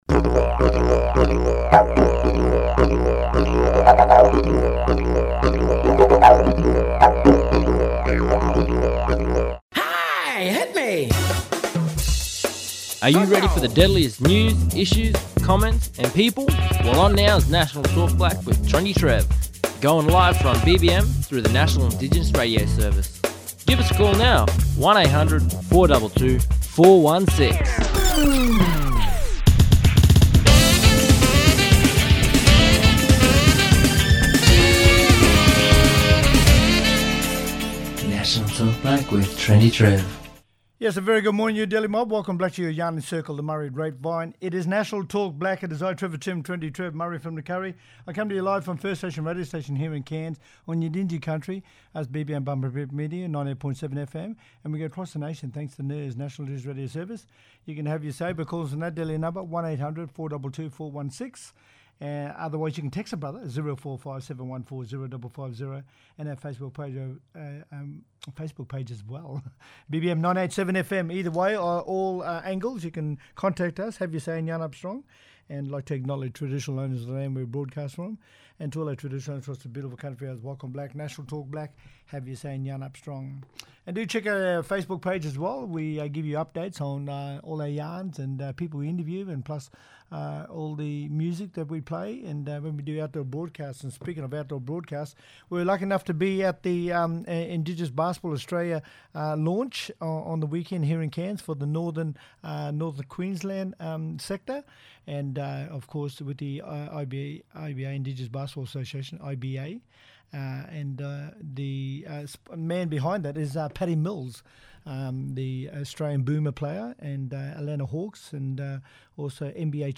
Patty Mills, Indigenous NBA player, talking